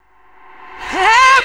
VOXREVERS2-R.wav